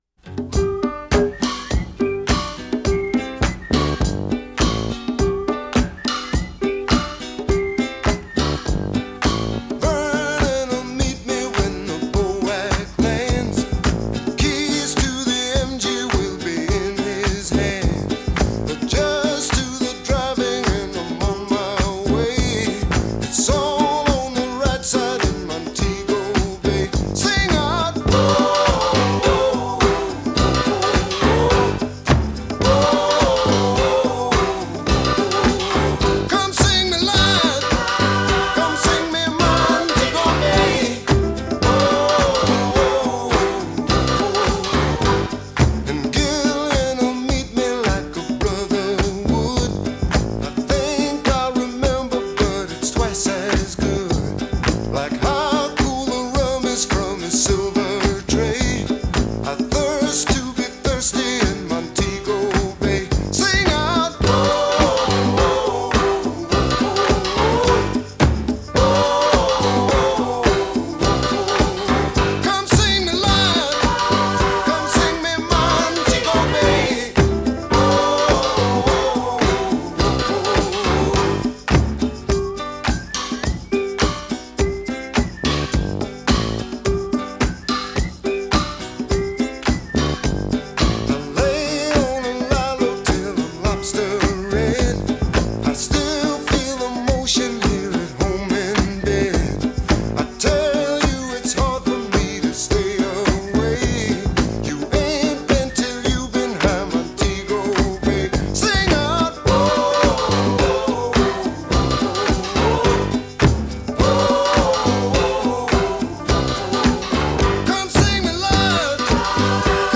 joyful
a cappella coda